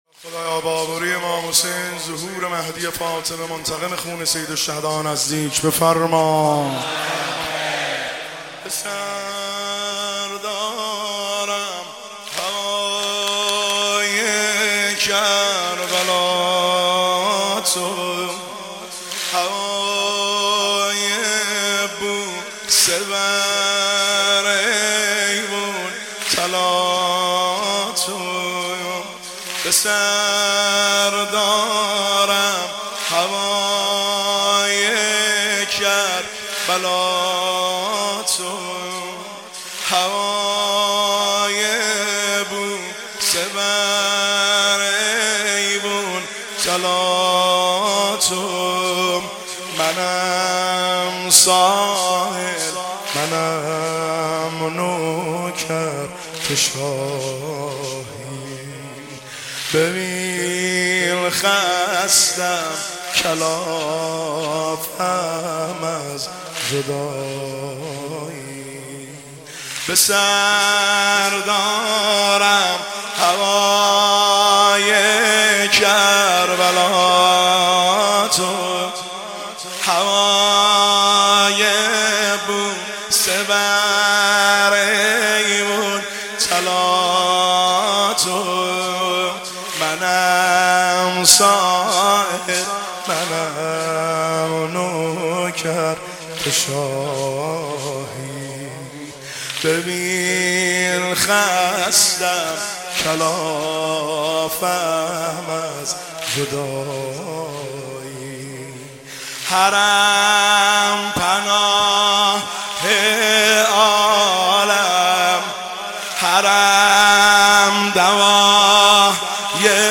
سنگین – شب چهارم محرم الحرام 1404